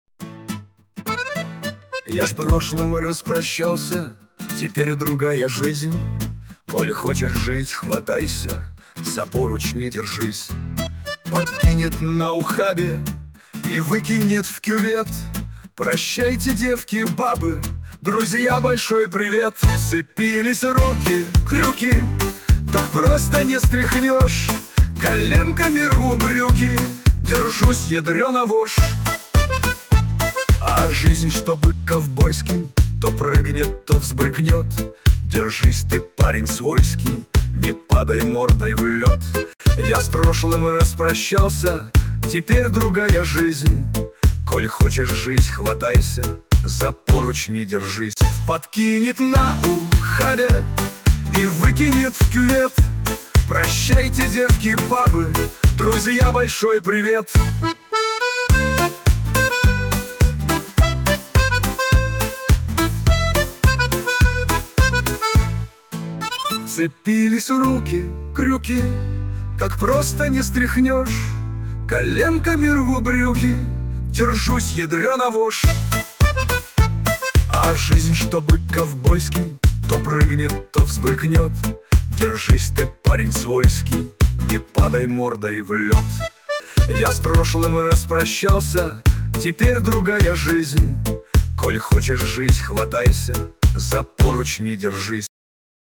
стиль 60-х гг.